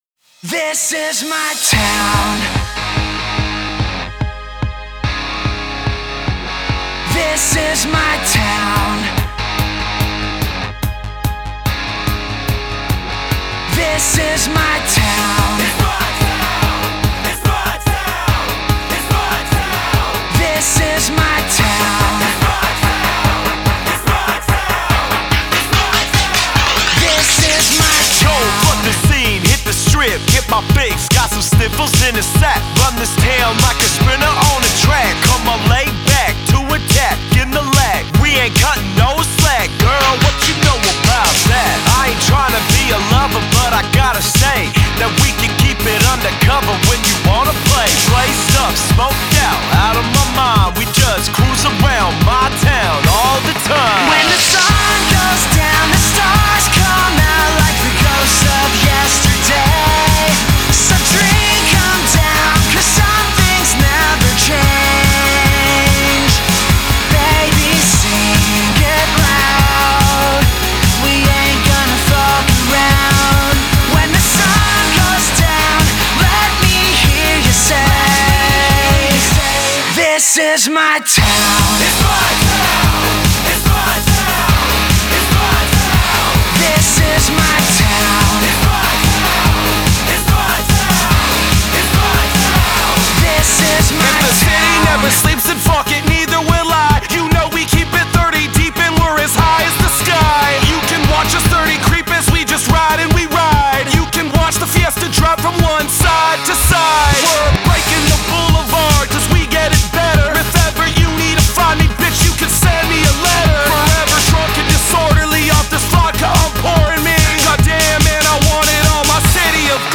Жанры: рэп-рок, рэп-метал, альтернативный рок,
электроник-рок, ню-метал,
кранккор, альтернативный хип-хоп